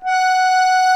F#5 ACCORD-R.wav